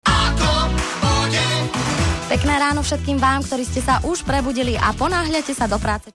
džingel "Ako bude?"
ok4-weather.mp3